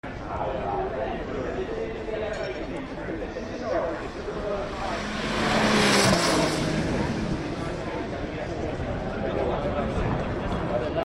1200hp Ferrari F80 V6 Sound Sound Effects Free Download